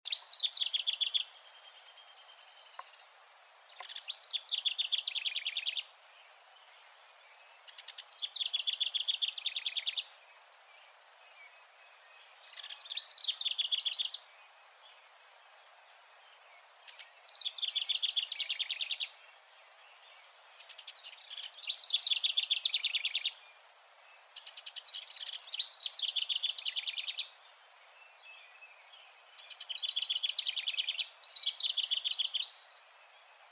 House Wren
Listen to the song of a male house wren: